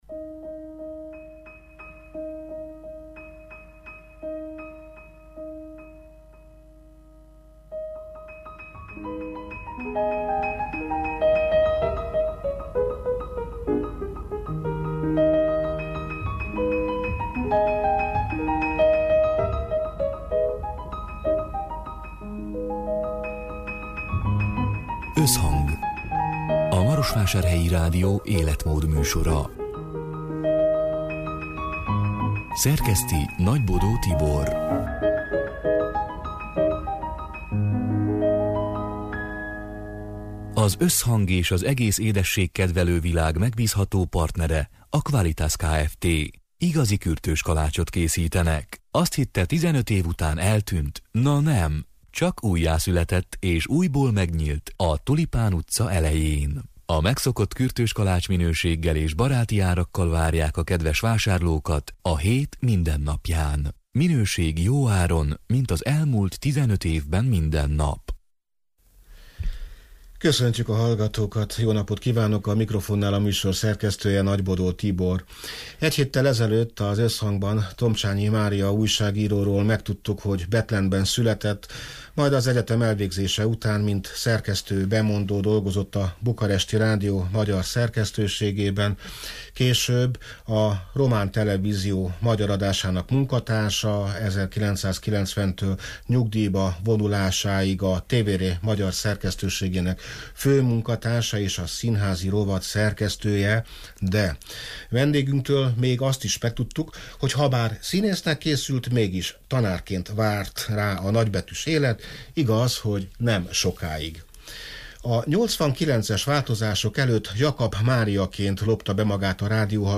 (elhangzott:2022. február 16-án, szerdán délután hat órától élőben)